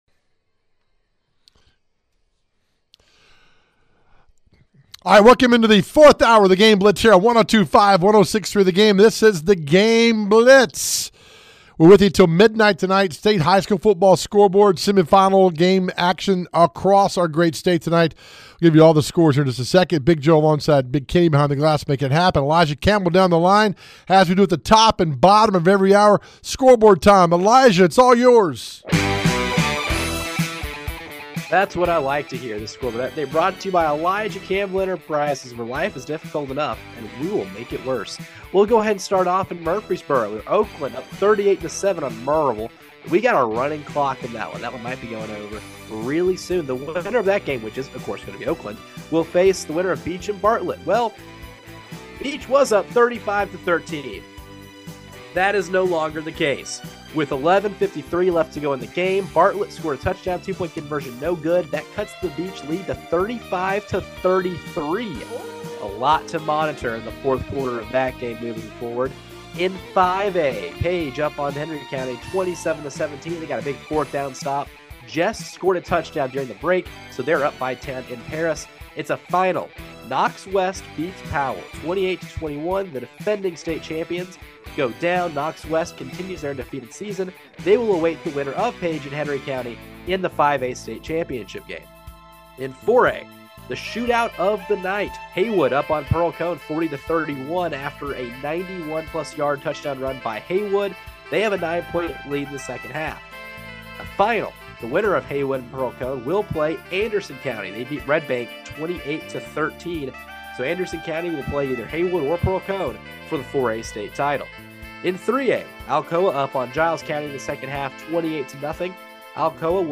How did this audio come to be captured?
We are in hour 4 of our season finale covering a great season of Middle TN High School Football! The playoffs are in full swing, and the guys have full coverage right here with reporters on location and conversations with team coaches!